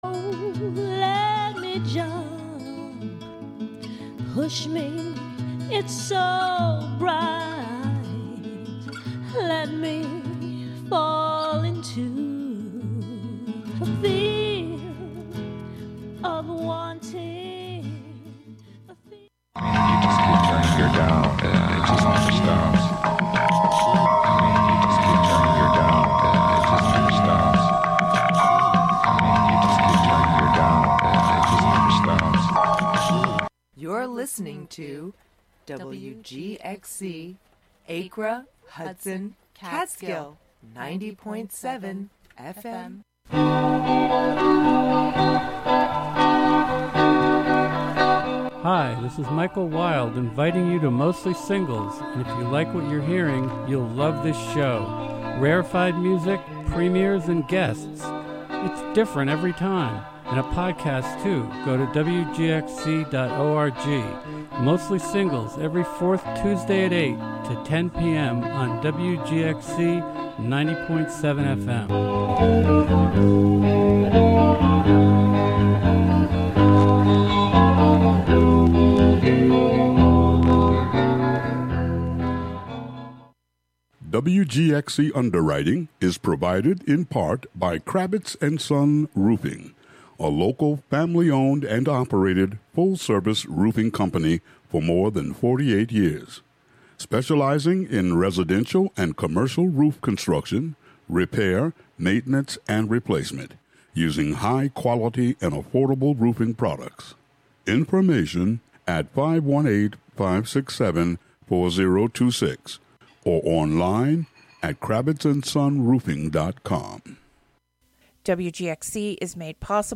Tryptophanfests are created to present an array of music so unlistenable that you would want to turn off the radio and go back to spending time with your hopefully less intolerable family.
Overlooked is a show focusing on overlooked and under-heard jazz, improvisational, and other fringe music, from early roots to contemporary experiments, a mix of familiar to rare, classic to weird.